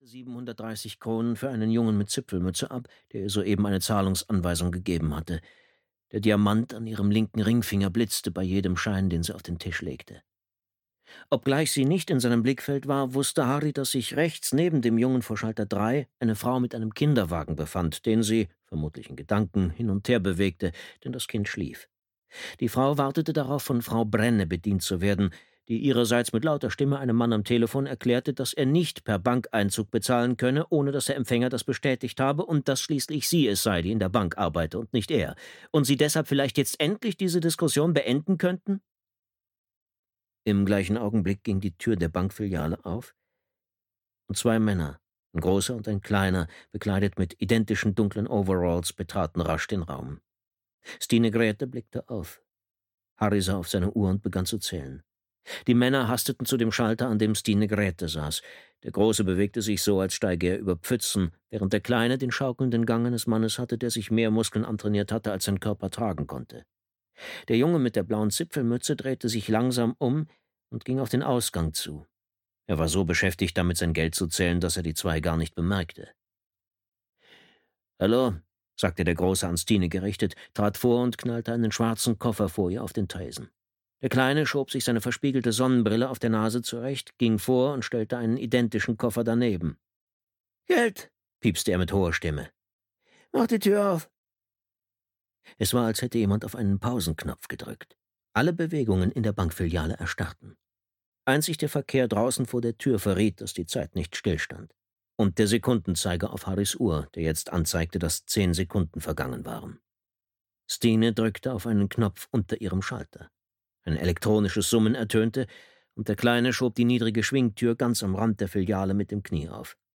Fährte (DE) audiokniha
Ukázka z knihy